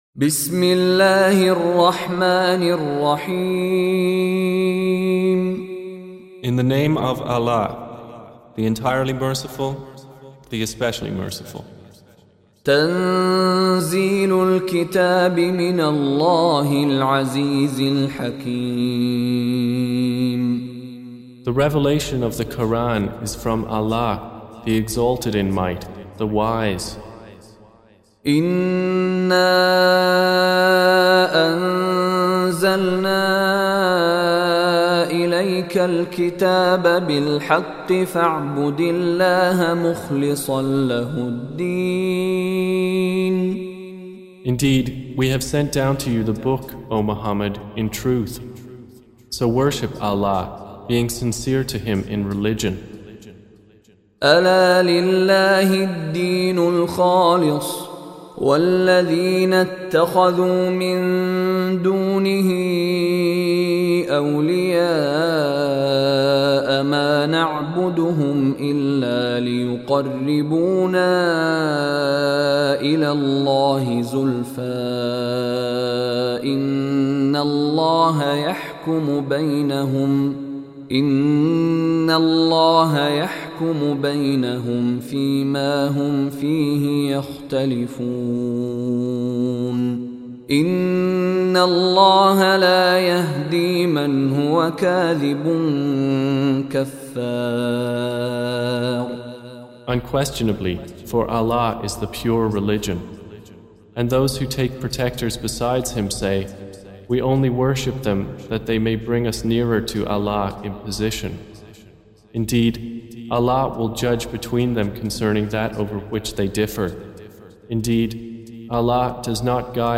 Surah Repeating تكرار السورة Download Surah حمّل السورة Reciting Mutarjamah Translation Audio for 39. Surah Az-Zumar سورة الزمر N.B *Surah Includes Al-Basmalah Reciters Sequents تتابع التلاوات Reciters Repeats تكرار التلاوات